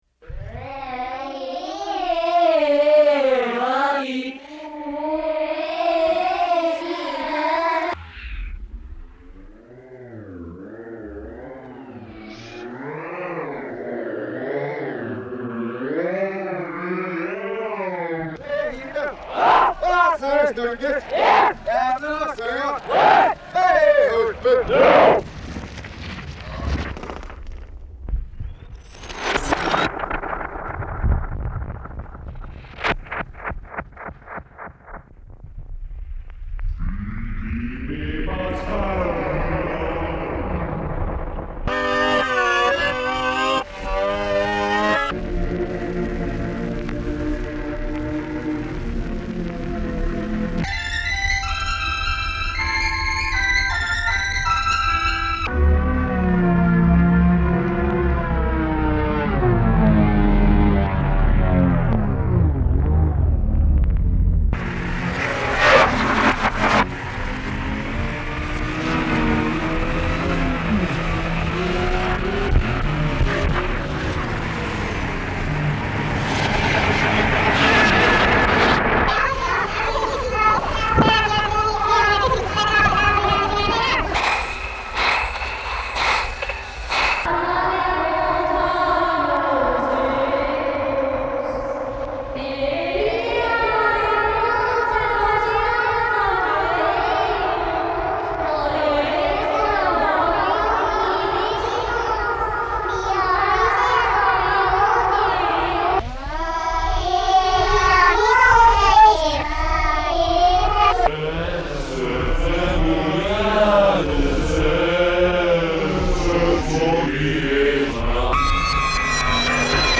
This was made from vinyl albums, hand rotated, and snippets of TV and shortwave radio.